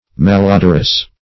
malodorous \mal*o"dor*ous\, a.